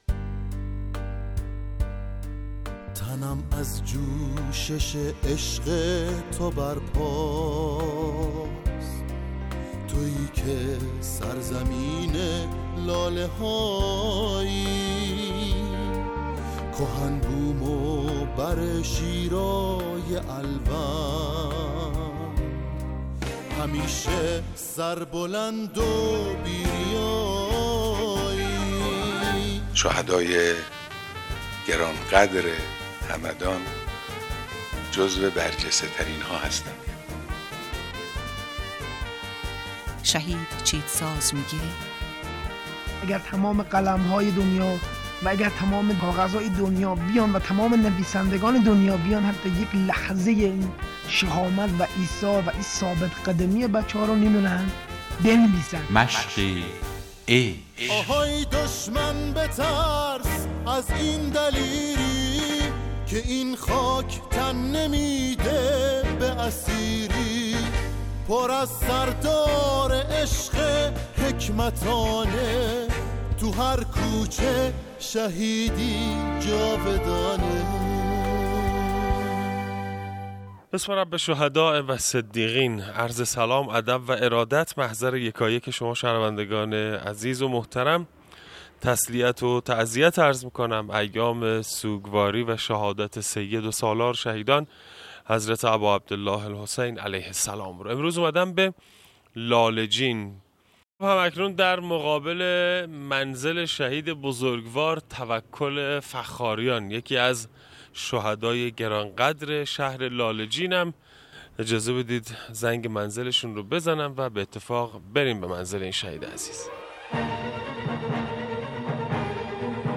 مصاحبه صوتی